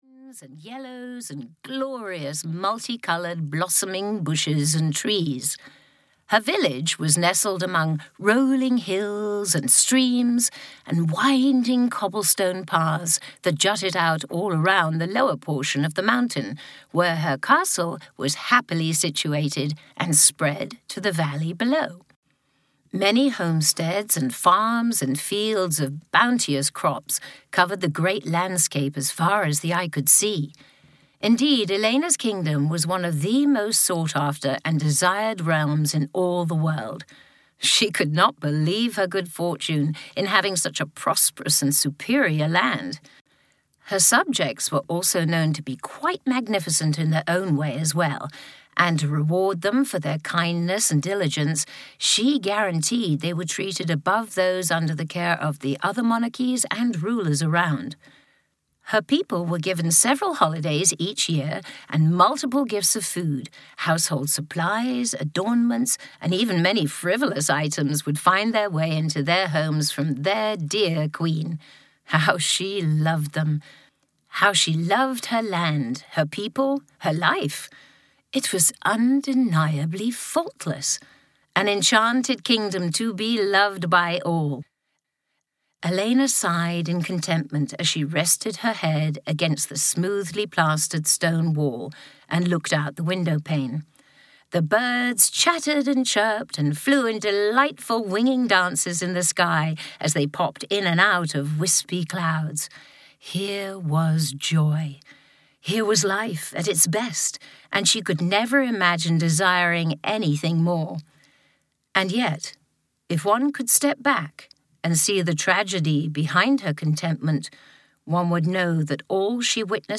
Sleeping Beauty (EN) audiokniha
Ukázka z knihy
• InterpretKaren Dotrice